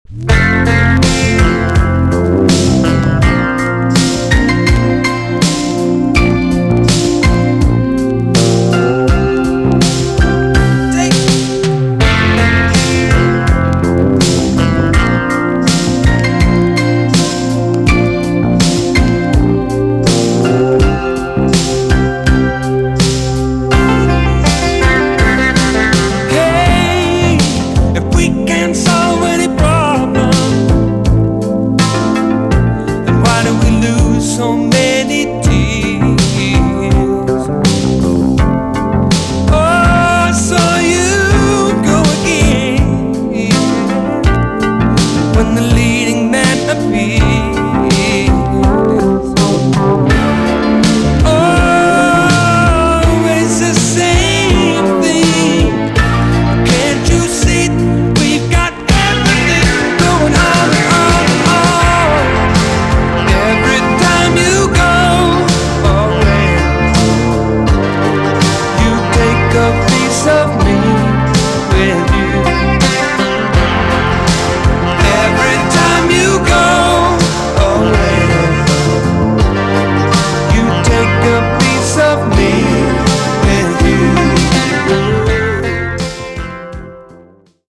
EX- 音はキレイです。
1985 , WICKED POP ROCK TUNE!!